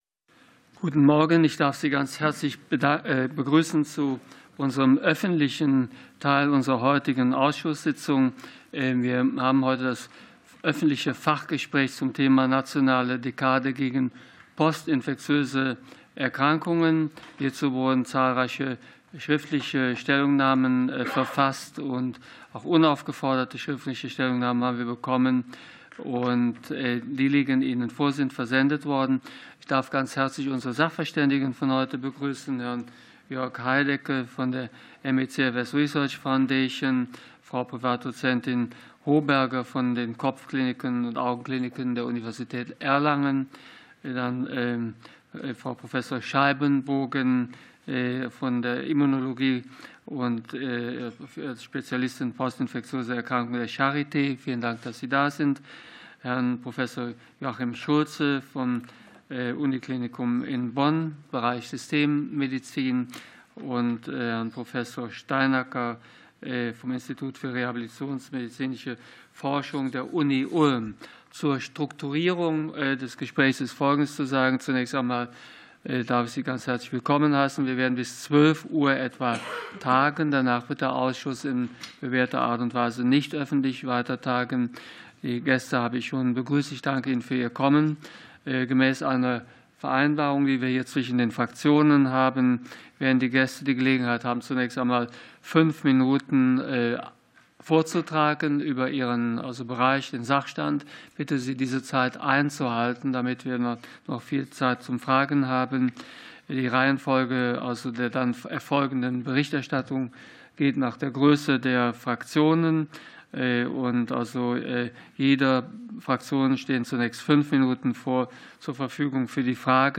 Fachgespräch des Ausschusses für Forschung und Technologie